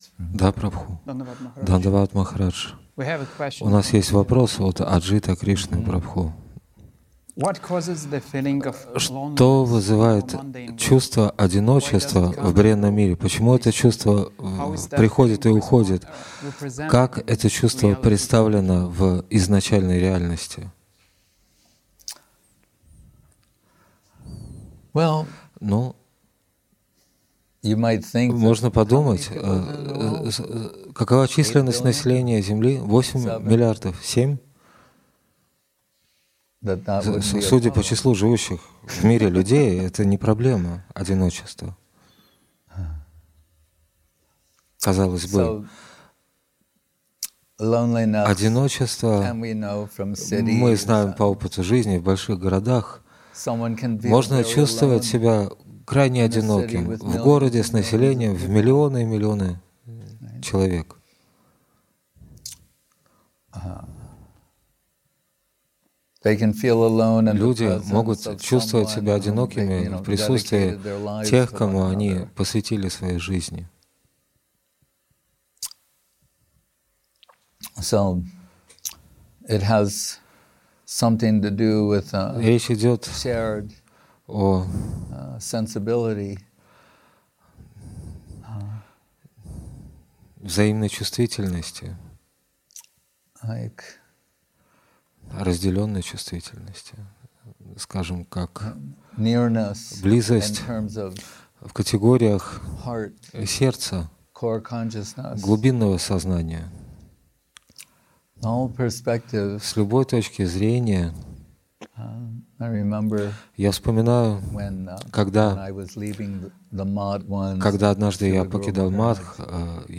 Place: Gupta Govardhan Chiang Mai